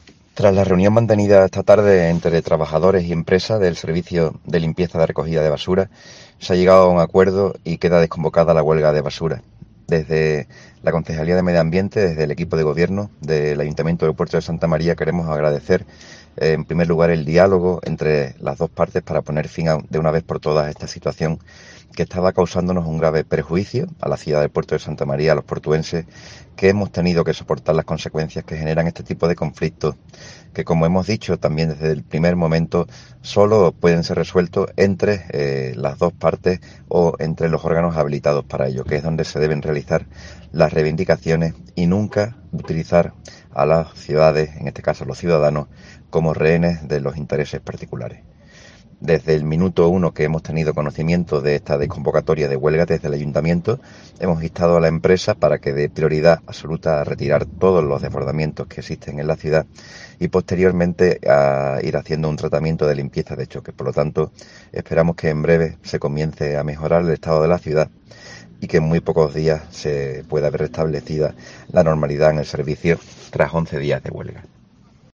Millán Alegre, concejal de Medio Ambiente del Ayuntamiento portuense, anuncia la desconvocatoria de la huelga